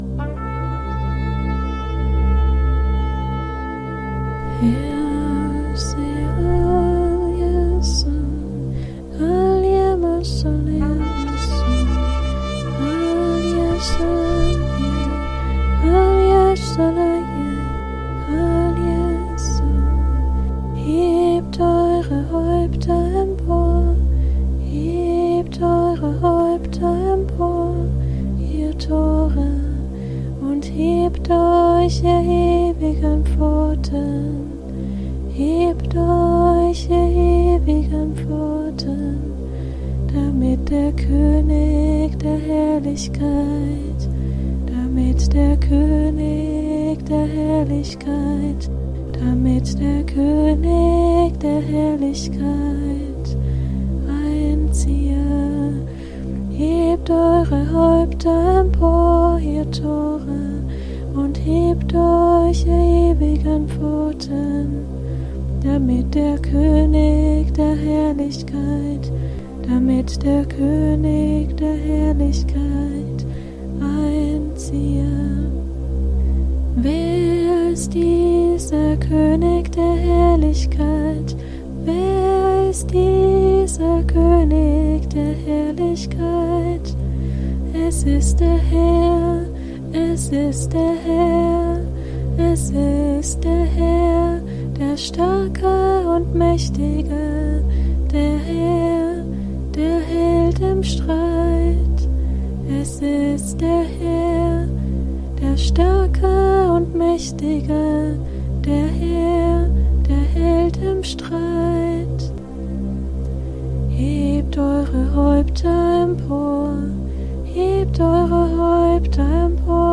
Und anschließend eine spontane Improvisation von Psalm 24, 7-10:
Das Geräusch des Shofars im Video stammt von hier.
Ansonsten Keyboard im Hintergrund.
psalm-24-als-lied-improvisation.mp3